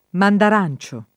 vai all'elenco alfabetico delle voci ingrandisci il carattere 100% rimpicciolisci il carattere stampa invia tramite posta elettronica codividi su Facebook mandarancio [ mandar # n © o ] s. m.; pl. -ci — cfr. clementina